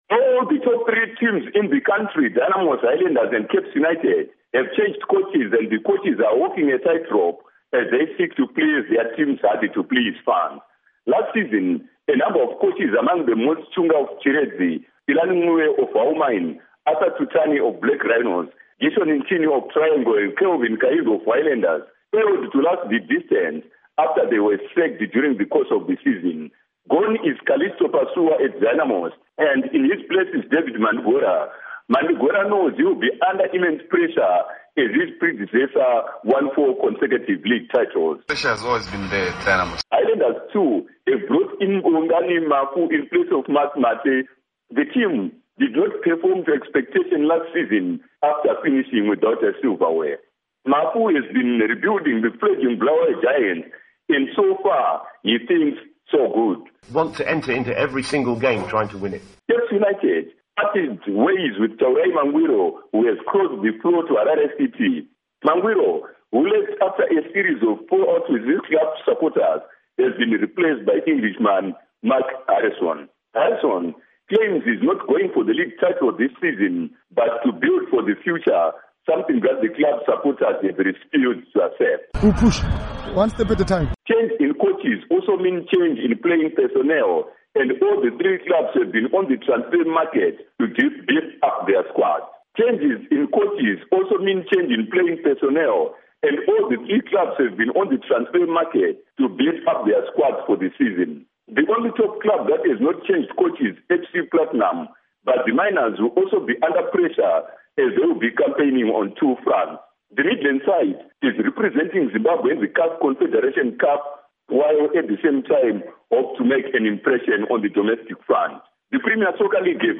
Zimbabwe Premier Soccer Report